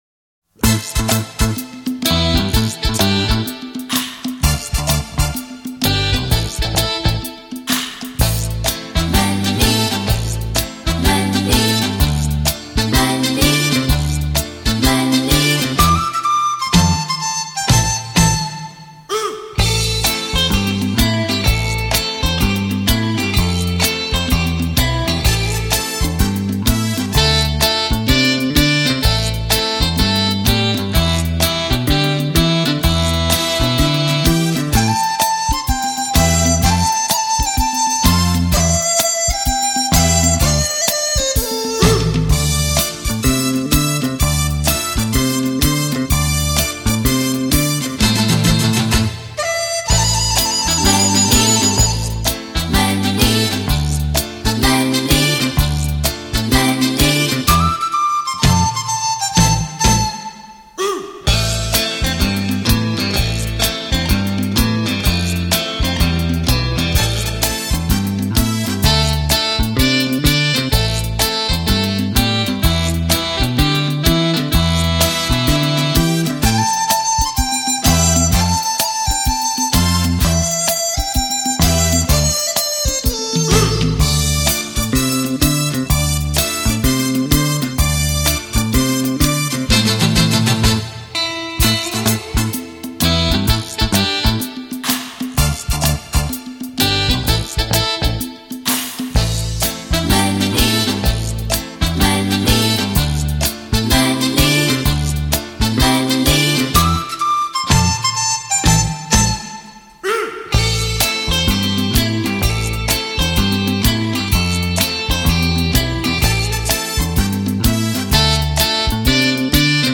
这是一张集合18首连贯的怀旧舞曲, 让你跳呀! 跳不完.